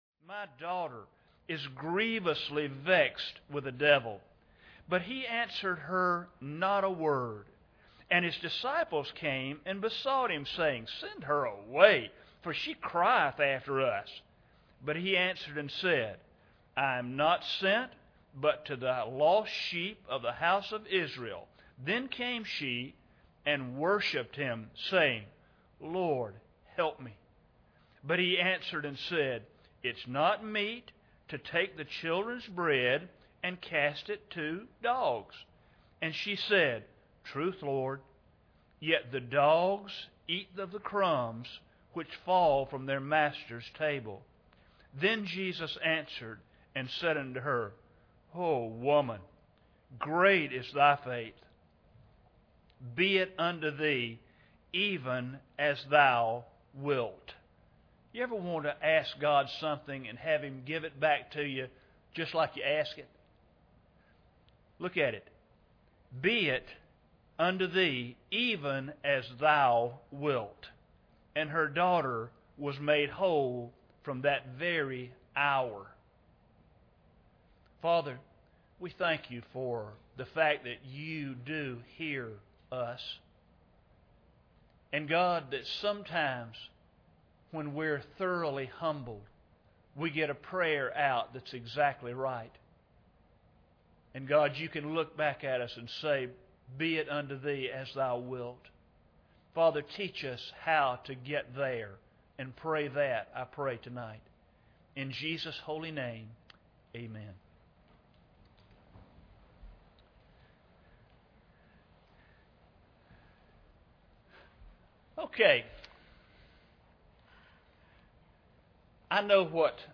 Matthew 15:21-28 Service Type: Sunday Evening Bible Text